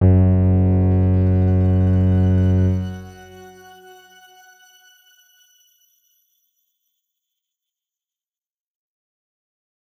X_Grain-F#1-mf.wav